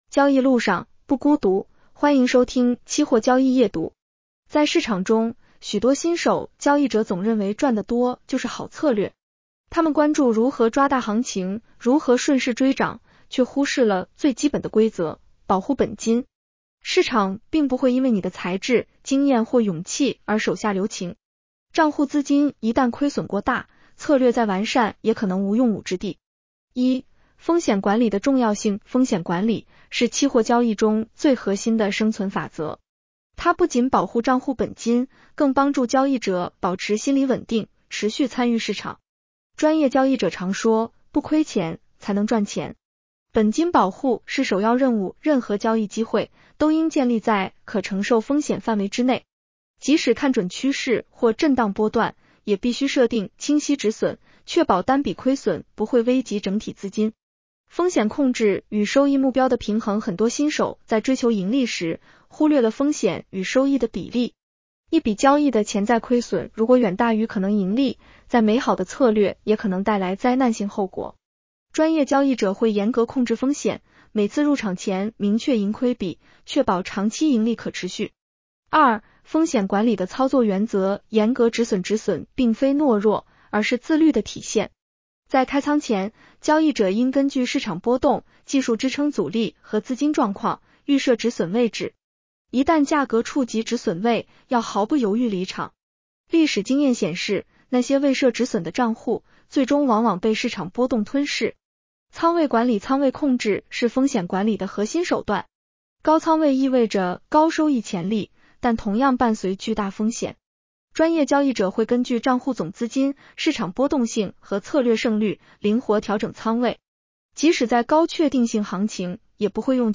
女声普通话版 下载mp3